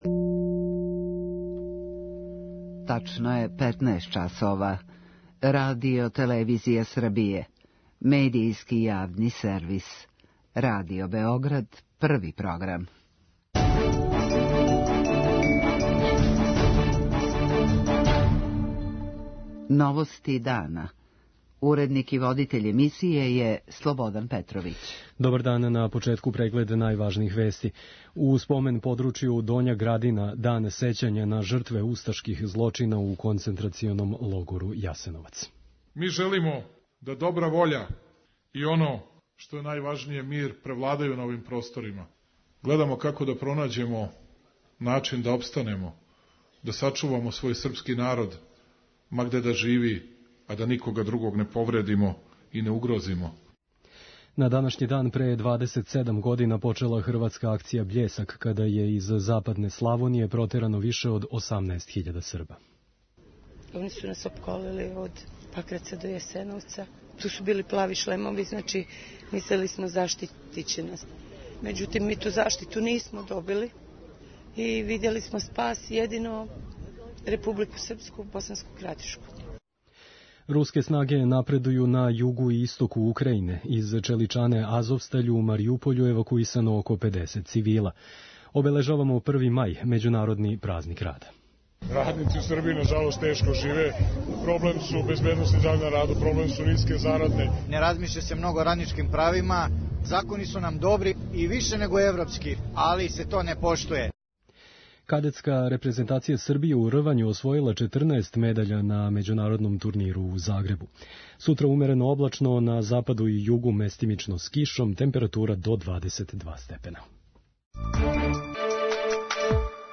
Међу страдалима за 1.337 дана постојања логора Јасеновац, било је скоро 20.000 деце. преузми : 6.08 MB Новости дана Autor: Радио Београд 1 “Новости дана”, централна информативна емисија Првог програма Радио Београда емитује се од јесени 1958. године.